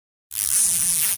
cable_tie_zipping.ogg